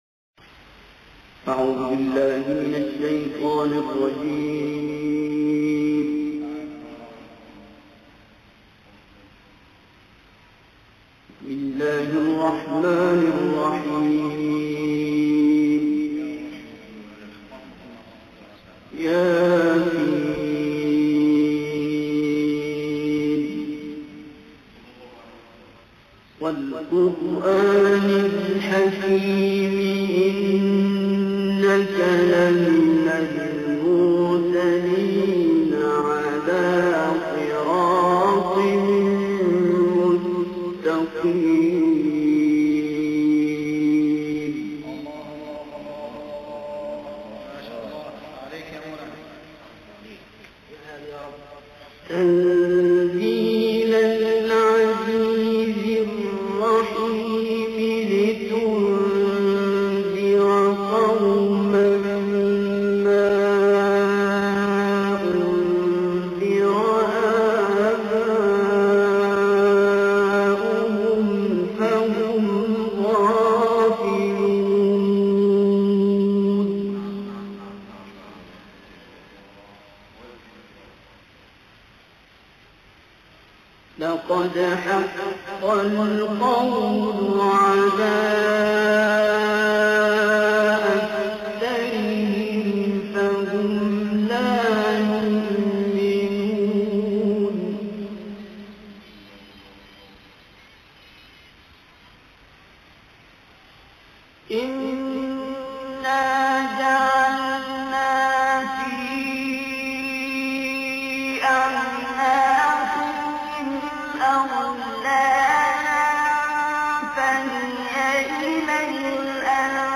القارئ عبد الباسط محمد عبد الصمد - سورة يس.
القارئ عبد الباسط محمد عبد الصمد - سورة يس....